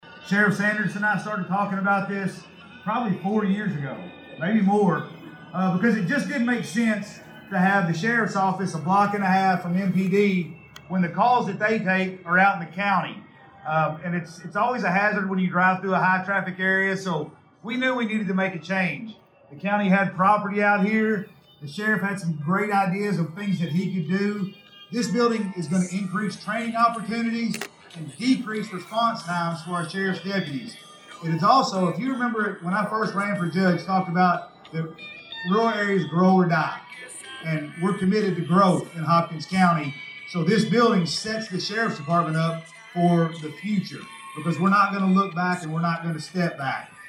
Judge-Executive Jack Whitfield welcomed everyone to the nearly $8 million facility, calling it a long-awaited achievement for the community.